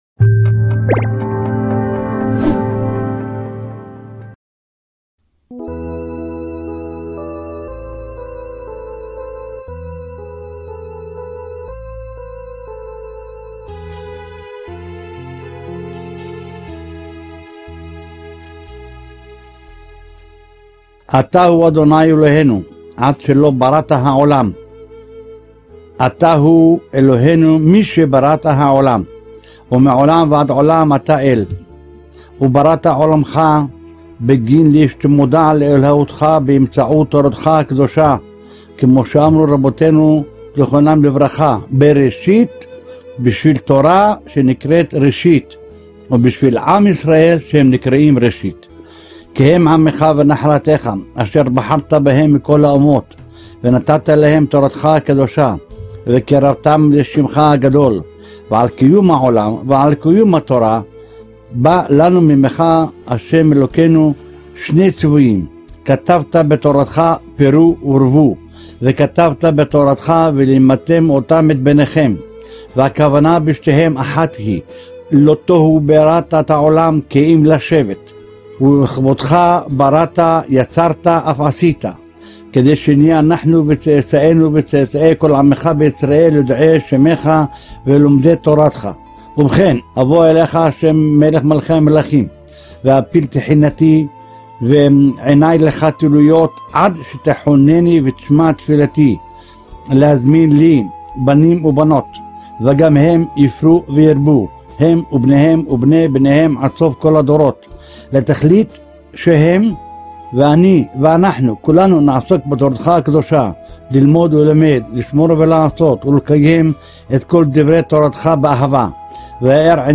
תפילת השל"ה הקדוש על הבנים בהקראתו של הראשון לציון הרב מרדכי אליהו זצוק"ל. האם מותר לכהנים לעלות למירון לקבר רשב"י - חלק מתשובת הרה"ג בן-ציון מוצפי שליט"א מספרו תורה בציון.